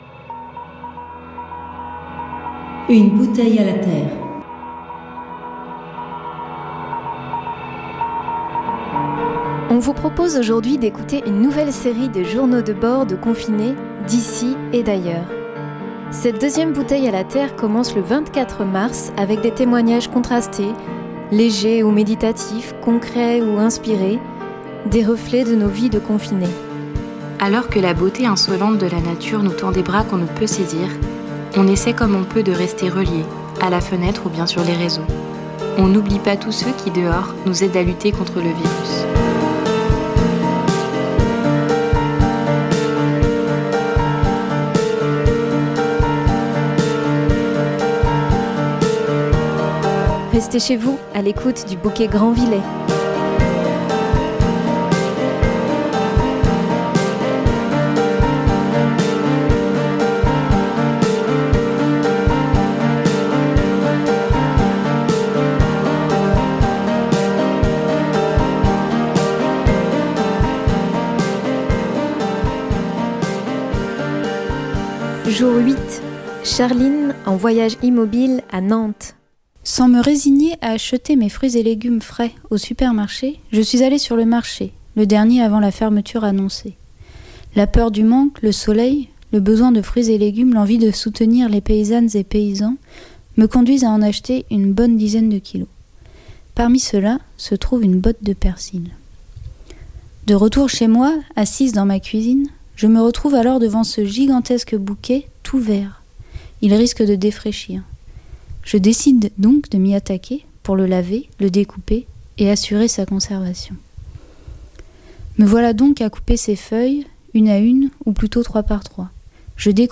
Cette deuxième bouteille à la terre commence le 24 mars avec des témoignages contrastés, légers ou méditatifs, concrets ou inspirés… des reflets de notre vie de confinés.